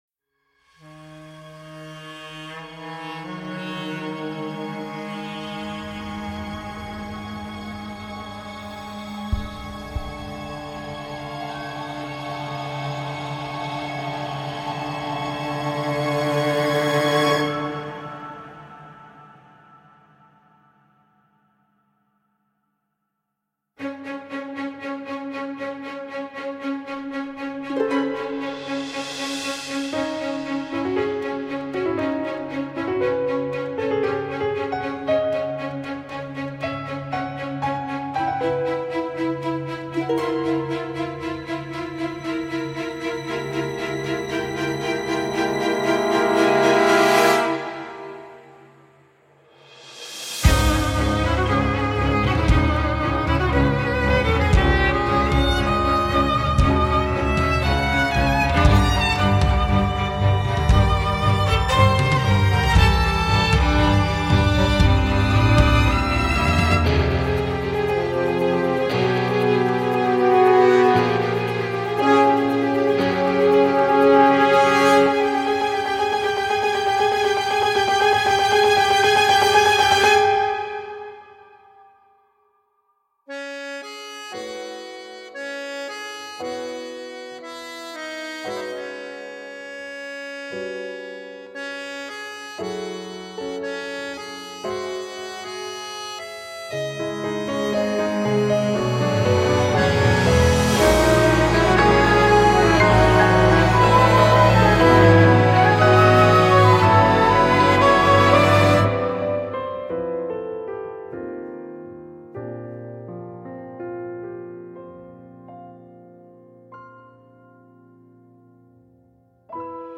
سبک موسیقی (Genre) موسیقی متن, موسیقی انیمه